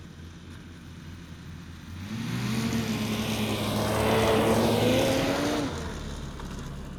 Snowmobile Description Form (PDF)
Internal Combustion Subjective Noise Event Audio File (WAV)